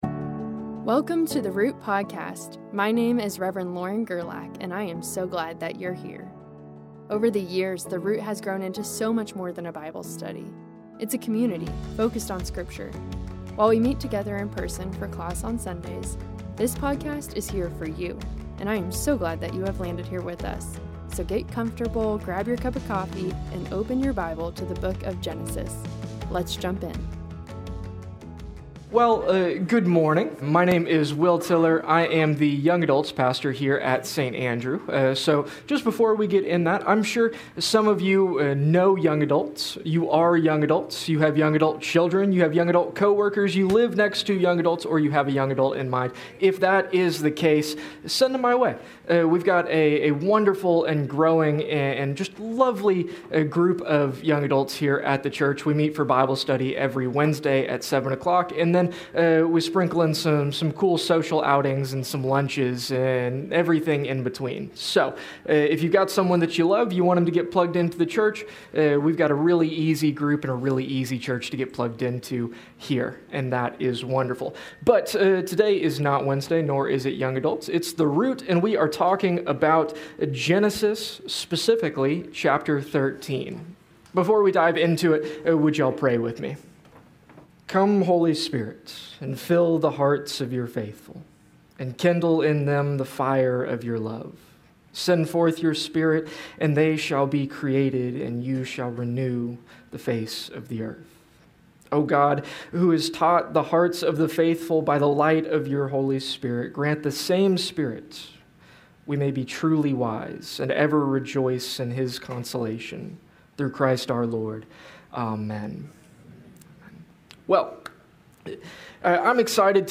A message from the series "Genesis."